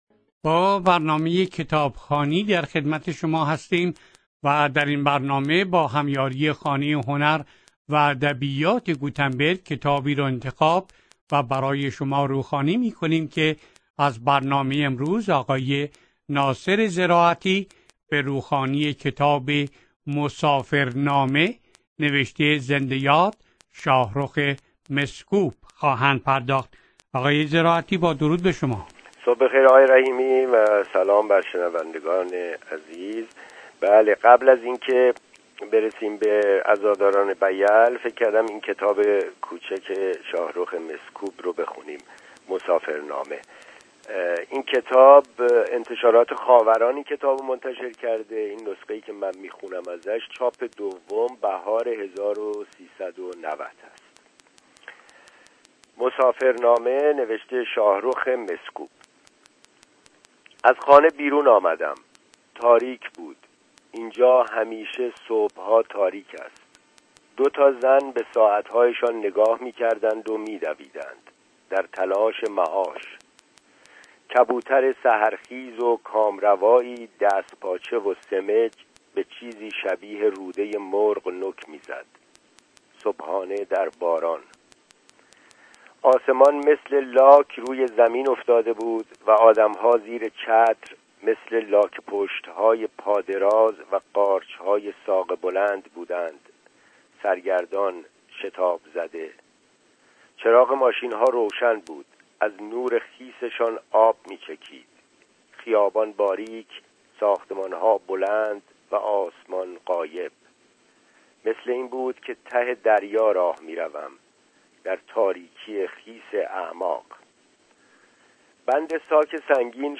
با هم این کتاب را می شنویم.